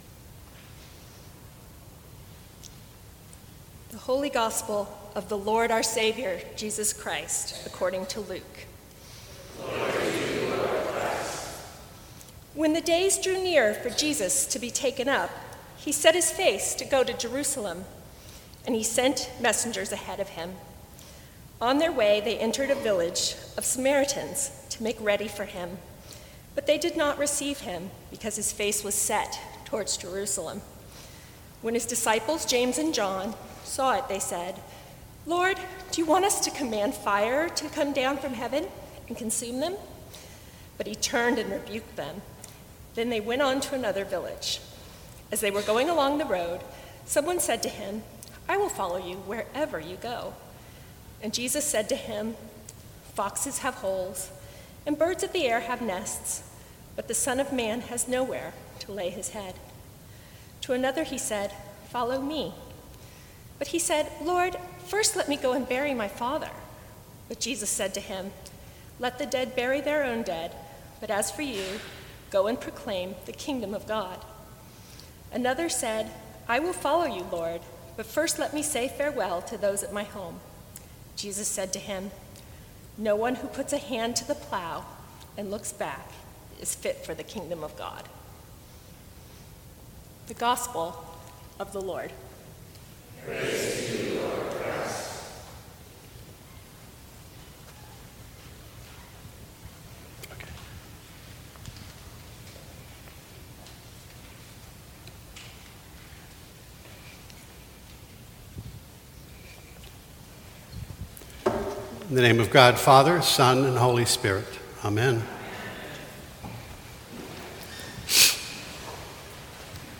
Sermons from St. Cross Episcopal Church Following Jesus is no easy task, for his peace is wildly different from the world’s peace.